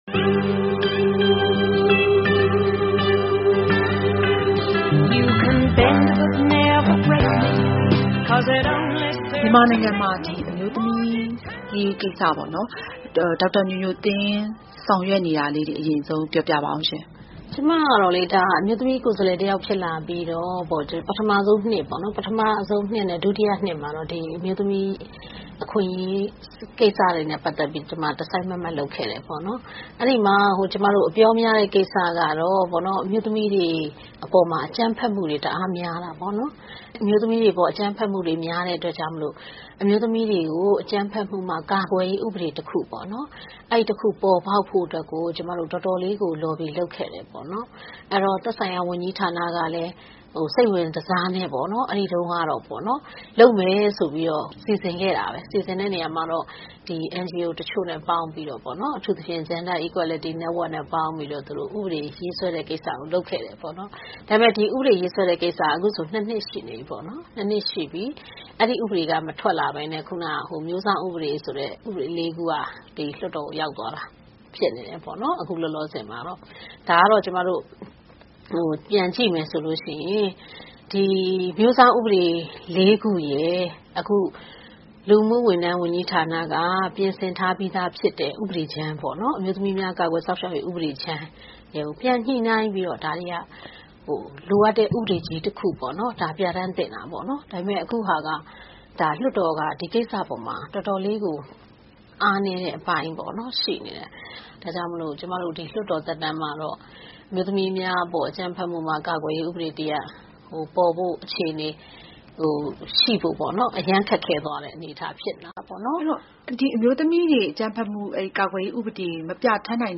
အမျိုးသမီးလွှတ်တော်အမတ် ဒေါက်တာညိုညိုသင်း နဲ့ ဗွီအိုအေမေးမြန်းခန်း